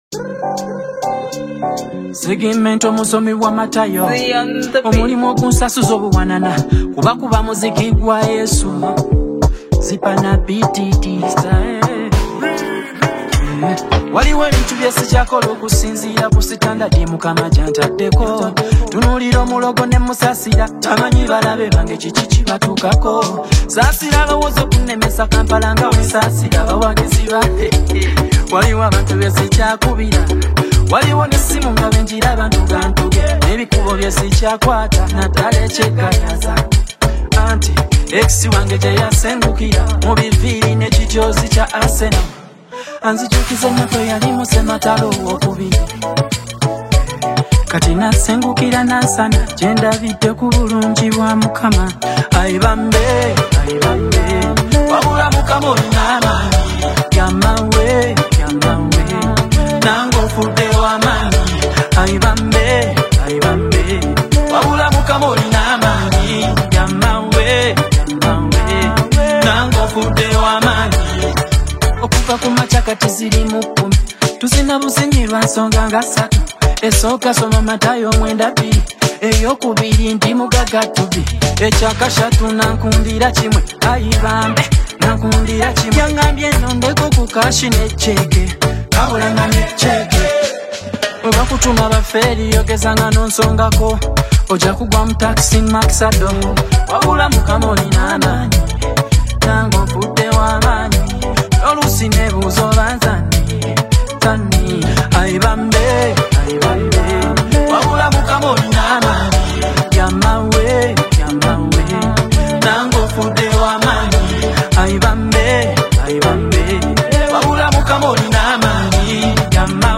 the song is an irresistible force of joy.